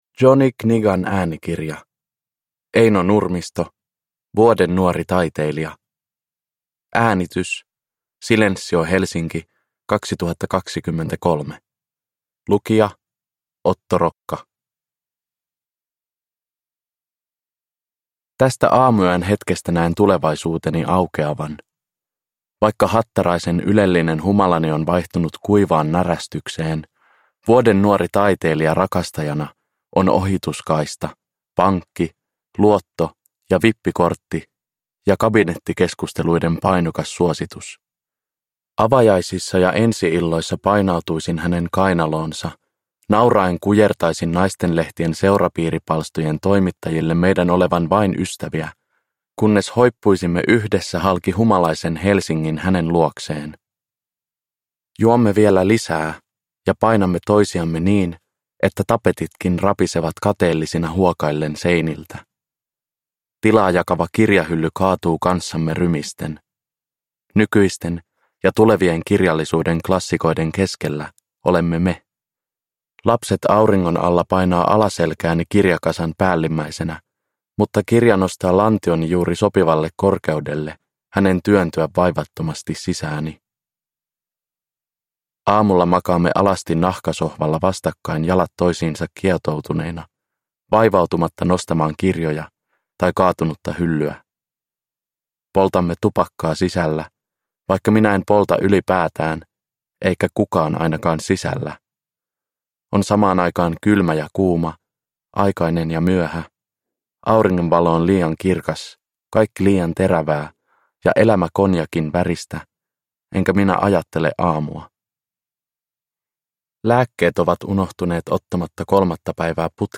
Vuoden nuori taiteilija – Ljudbok